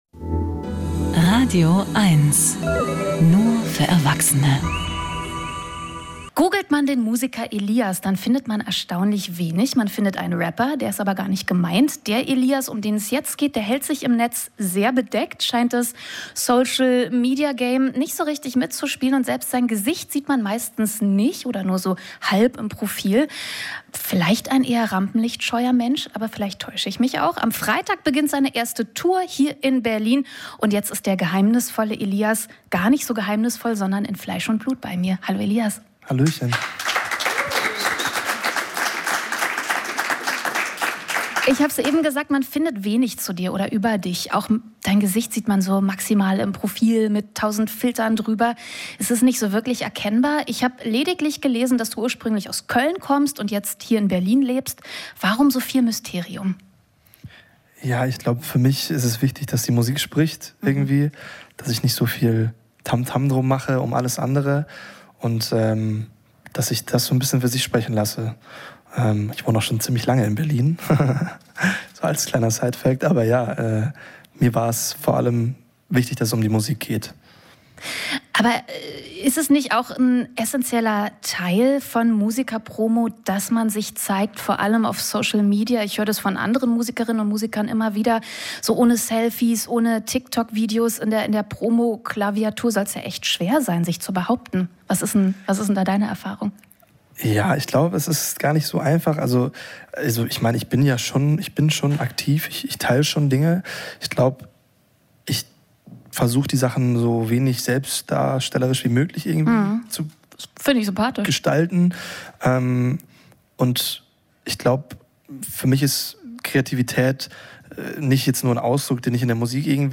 Musik-Interviews
Die besten Musikerinnen und Musiker im Studio oder am Telefon gibt es hier als Podcast zum Nachhören.